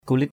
/ku-lɪt/ (d.) công việc, lao động. tuk kulit t~K k~l{T giờ lao động.
kulit.mp3